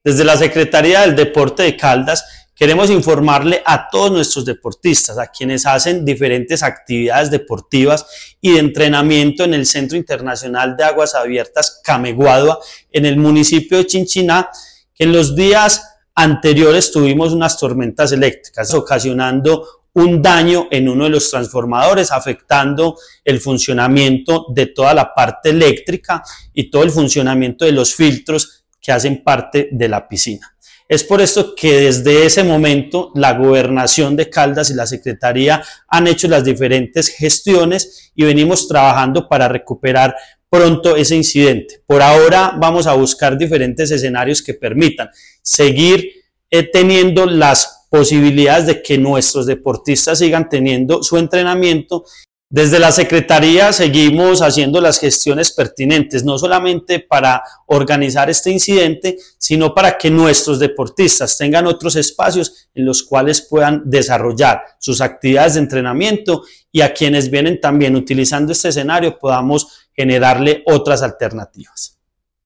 Secretario de Deporte de Caldas, Andrés Duque.
Andres-Duque.mp3